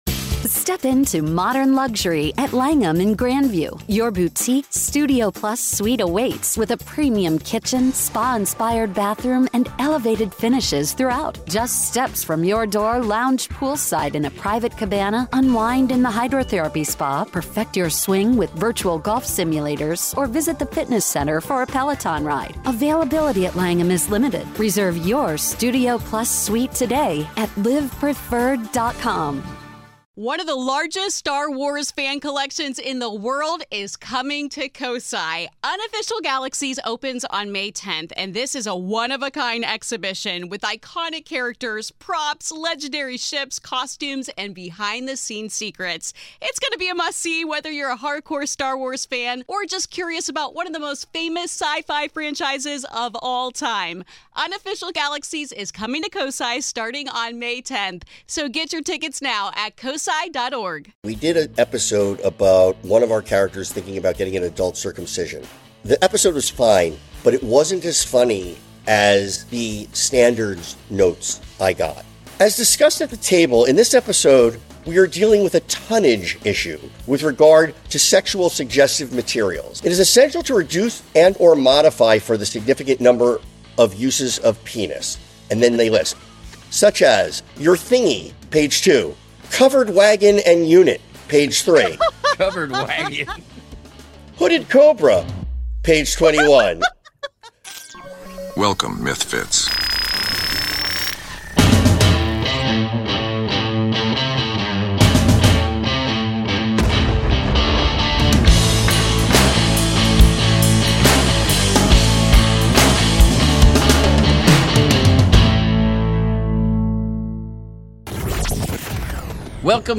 This week on MythFits, Kari and Tory are joined by Modern Family producer and comedy mastermind Danny Zuker! They launch into a chaotic conversation about Pumpkin Chunkin mayhem, the creeping rise of AI in writers’ rooms, and a Flying Anvils story so wild it ends with someone mysteriously… missing a thumb.